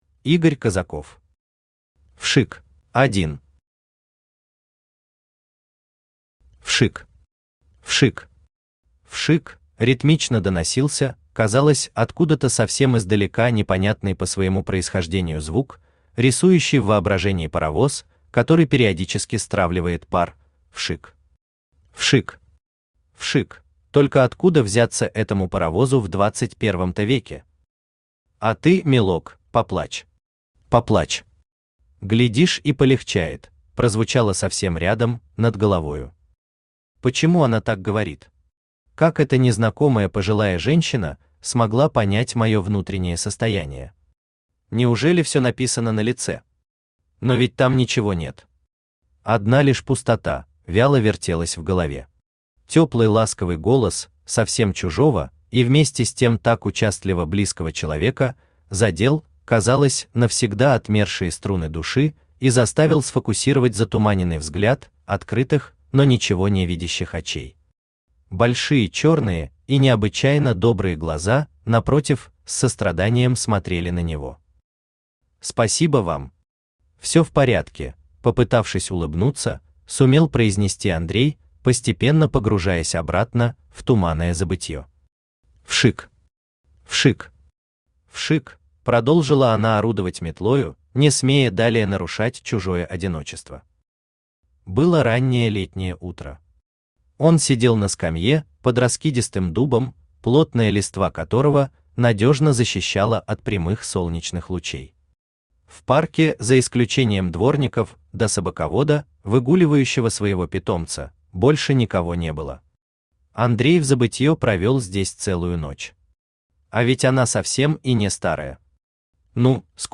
Аудиокнига Вшик | Библиотека аудиокниг
Aудиокнига Вшик Автор Игорь Козаков Читает аудиокнигу Авточтец ЛитРес.